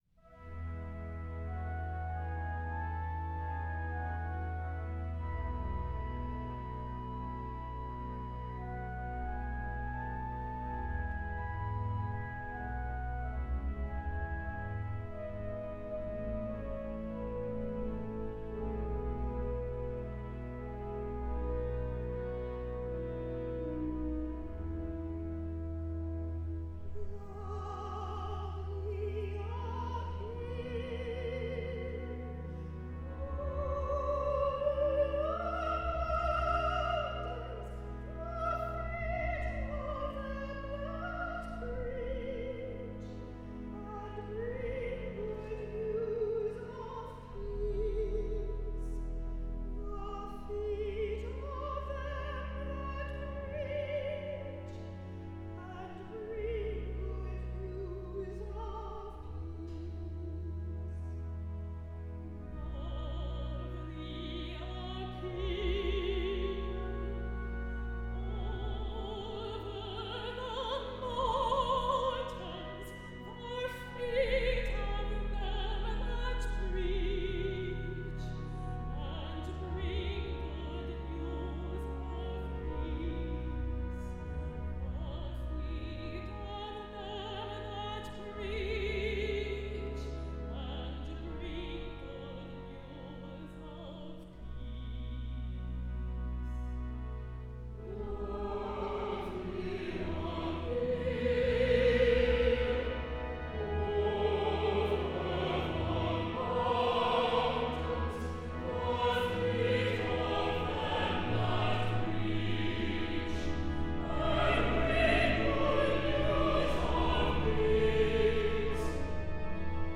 Hymns from All Saints, Haverhill
an ensemble of Boston-area vocalists recorded the following hymns at All Saints Catholic Church in Haverhill, Massachusetts
organ
Choir:
soprano
alto